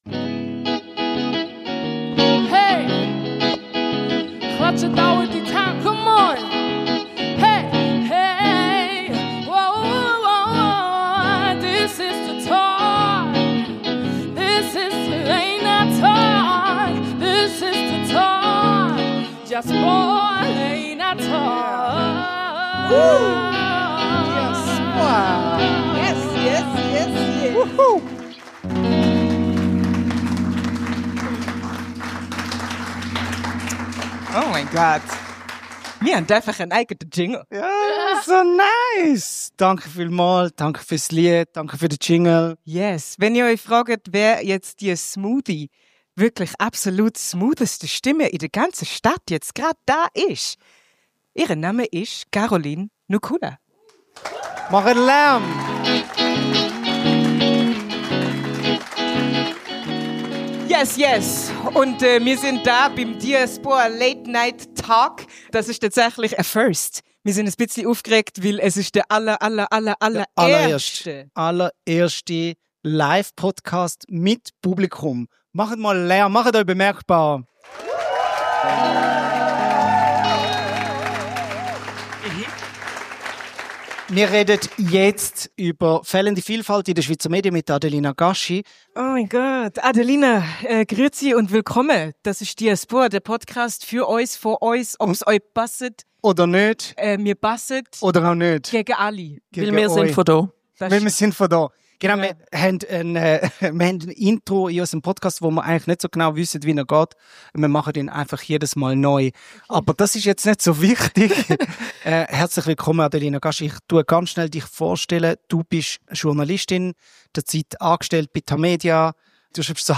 Beschreibung vor 10 Monaten Das ist die erste von drei Folgen der DIASBOAH Late Night Talks, aufgenommen am 21. März 2025 in der Kornhausbibliothek Bern.